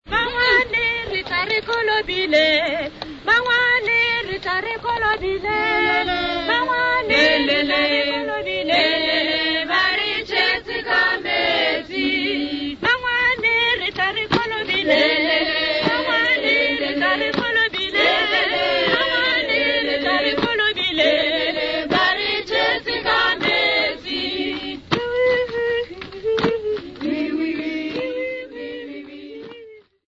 Sesotho boys and girls (Performer)
Shongoane village
Dance music
Wedding song
Clapping
Traditional music
Sesotho traditional wedding song with clapping accompaniment
Cassette tape